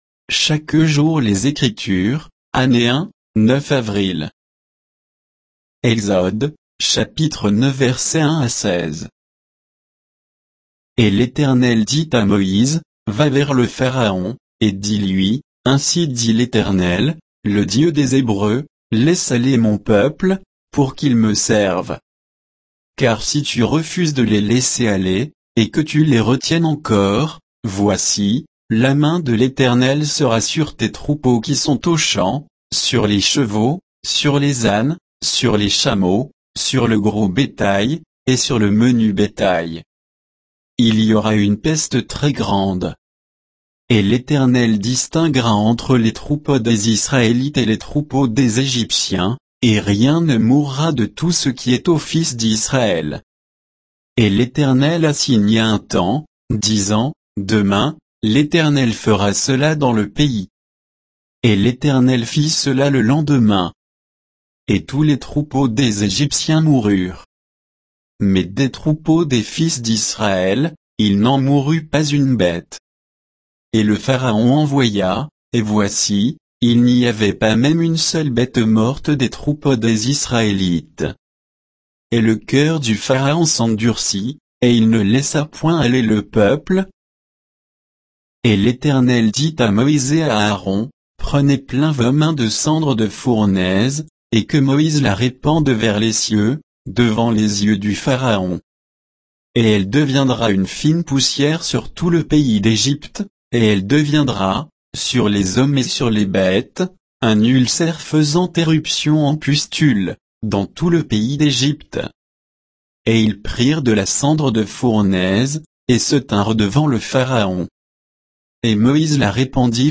Méditation quoditienne de Chaque jour les Écritures sur Exode 9, 1 à 16